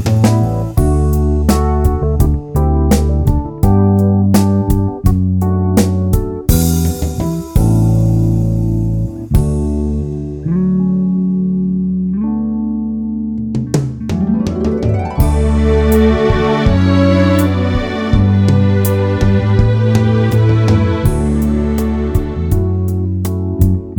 Minus Piano Pop (1970s) 3:49 Buy £1.50